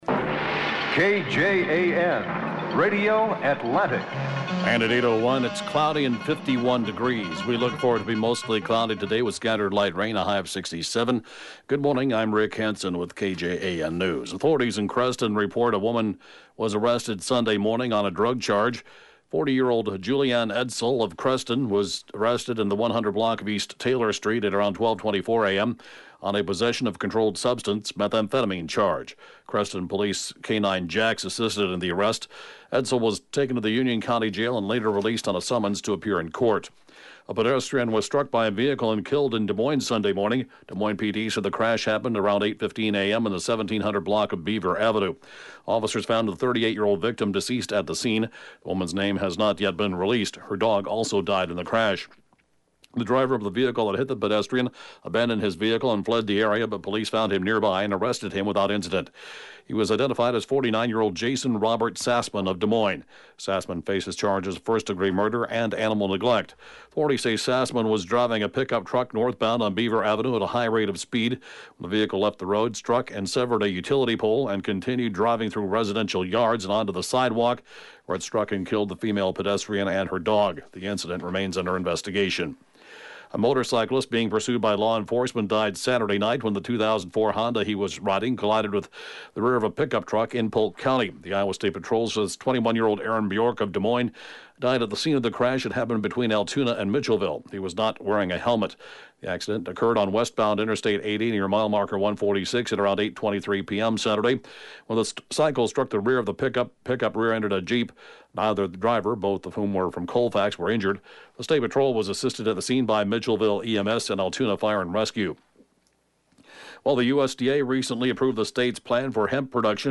(Podcast) KJAN 8-a.m. News, 4/6/20